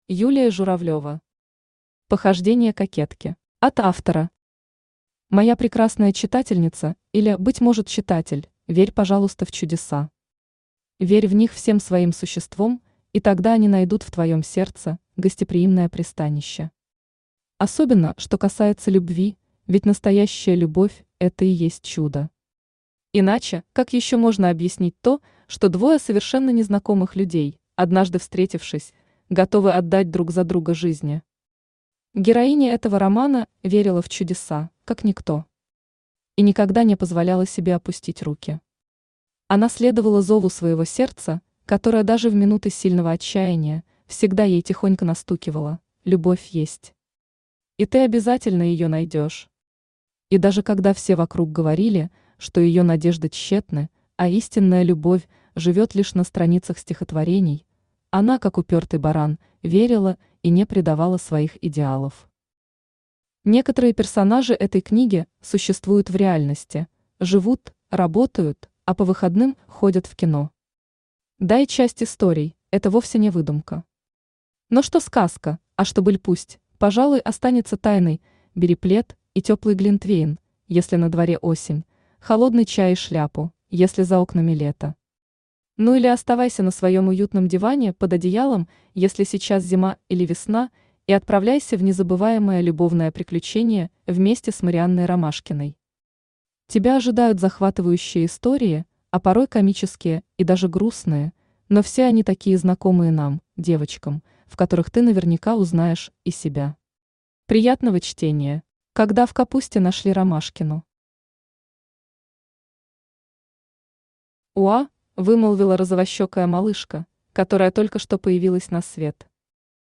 Аудиокнига Похождения кокетки | Библиотека аудиокниг
Aудиокнига Похождения кокетки Автор Юлия Сергеевна Журавлева Читает аудиокнигу Авточтец ЛитРес.